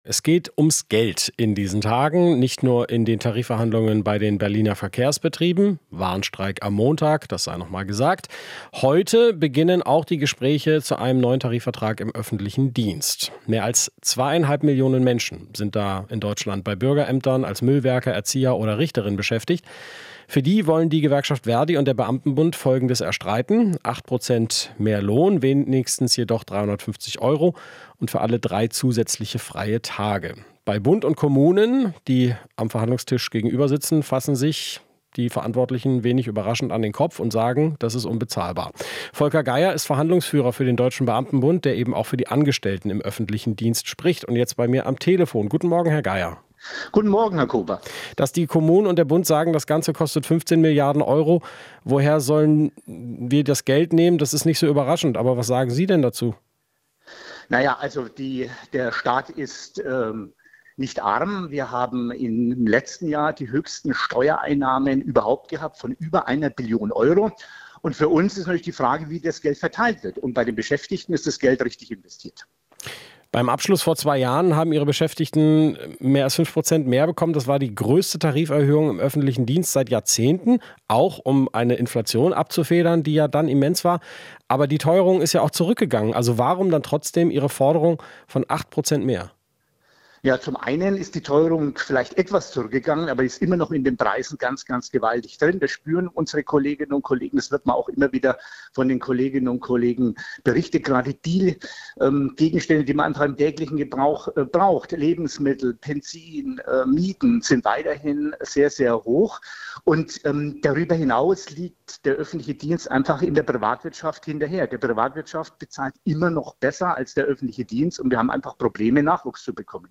Interview - Tarifrunde: DBB fordert mehr Geld für öffentlichen Dienst